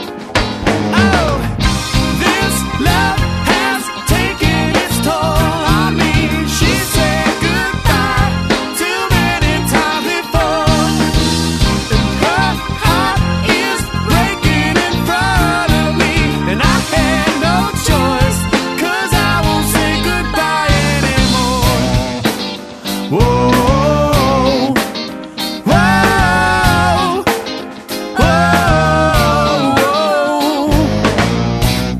dazzling vocals